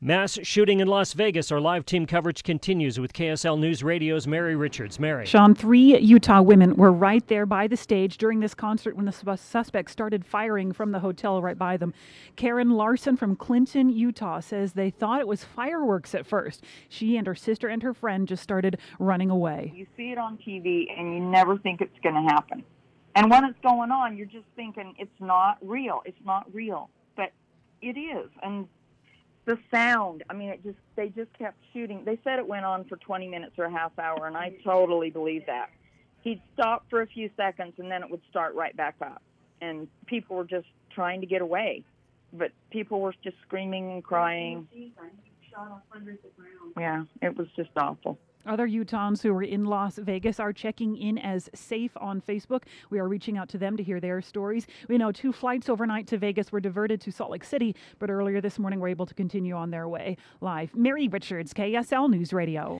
Utahns talk about horror of Las Vegas mass shooting